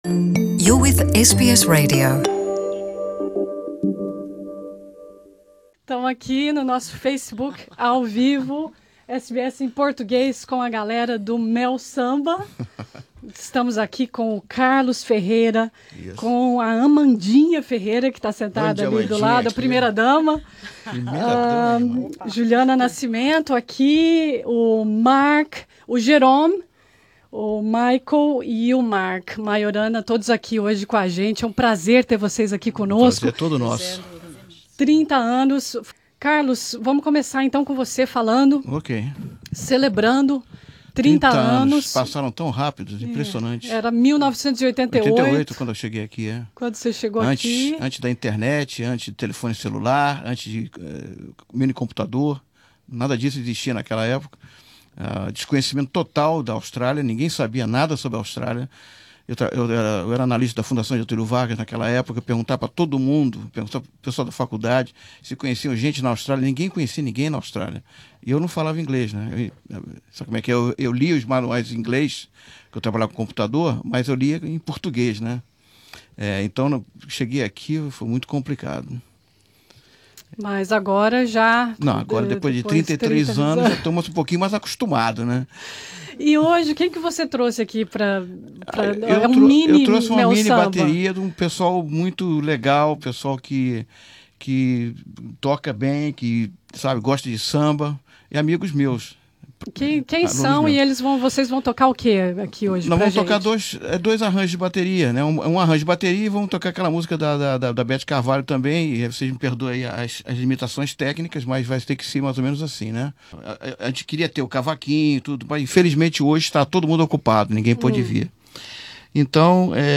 Nos estúdios da rádio SBS em Melbourne